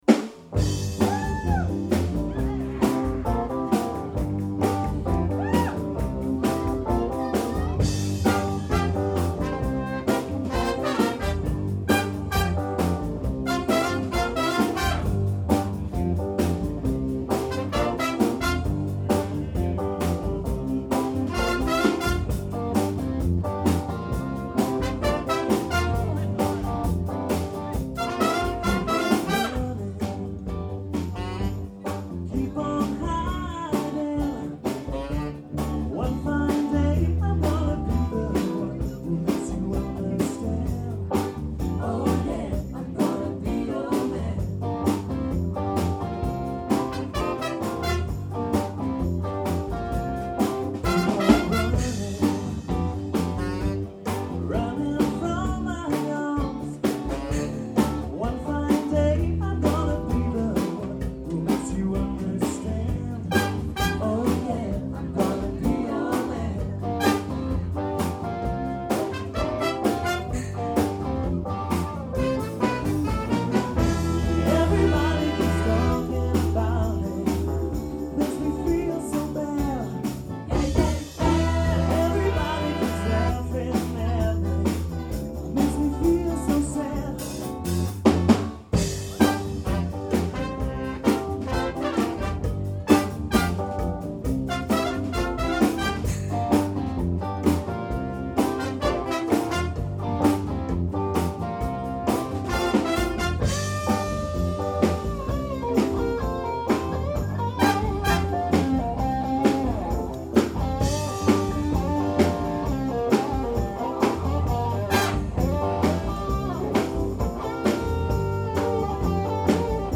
Big  Band - Rythm'n Blues